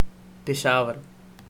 Peshawar_pronunciation.ogg.mp3